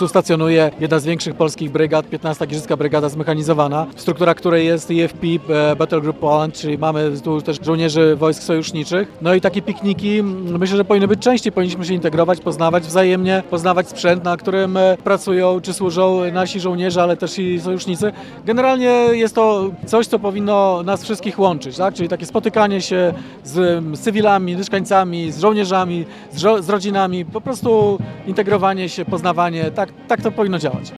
Burmistrz Giżycka Wojciech Karol Iwaszkiewicz dodaje, że takie pikniki są dobrą okazją do integracji.